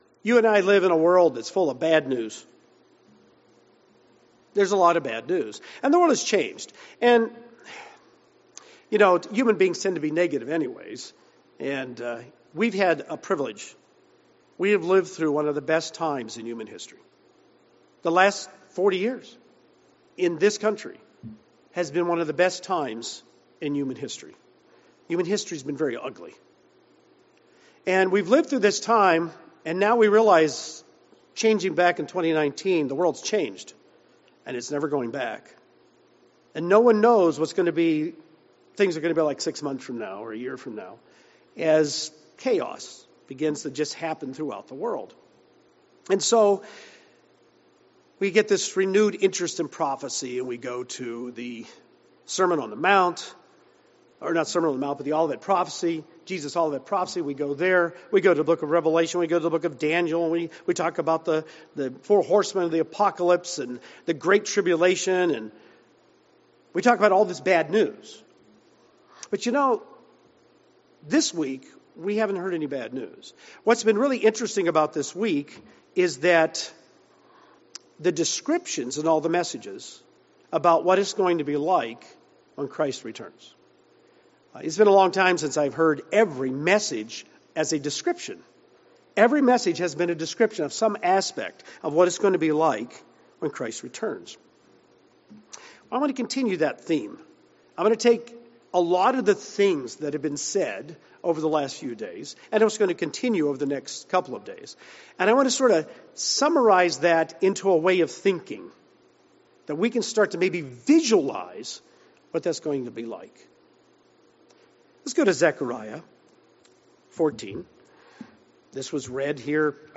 This sermon was given at the Branson, Missouri 2022 Feast site.